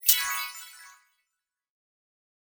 Special & Powerup (20).wav